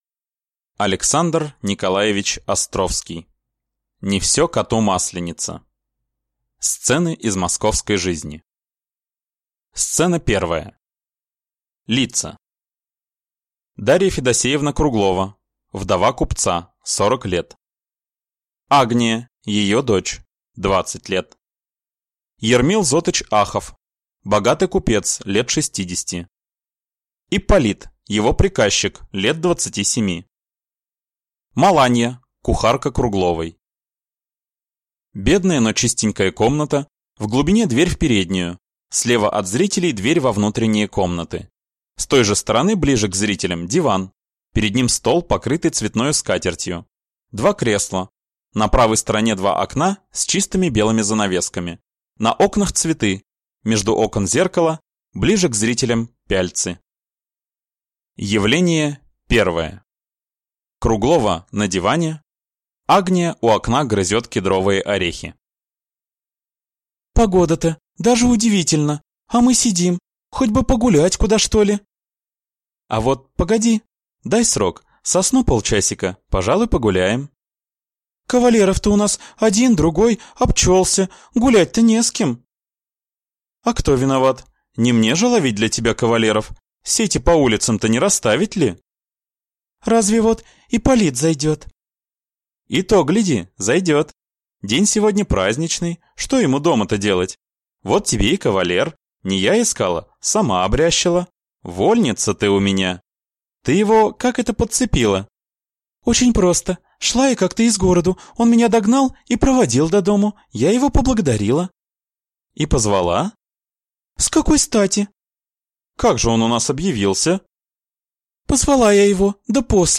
Аудиокнига Не все коту масленица | Библиотека аудиокниг